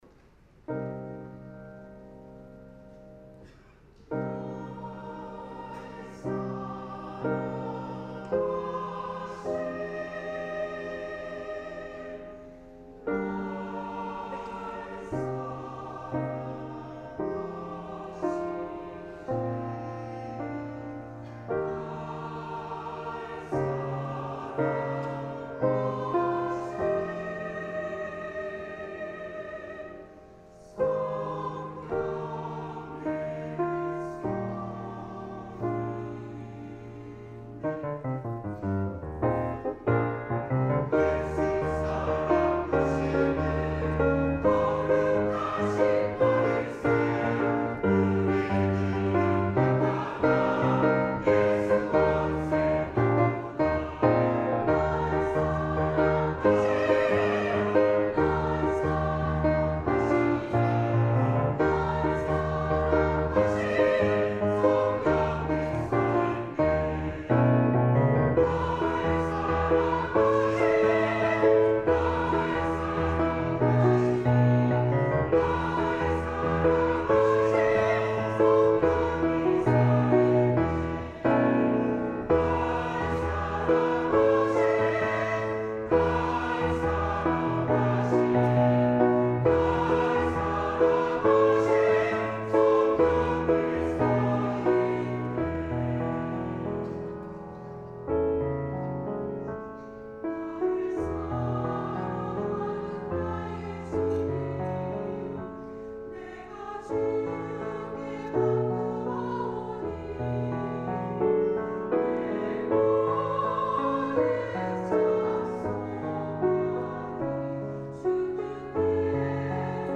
찬양 :: 예수 사랑하심
예수 사랑하심 - 시온찬양대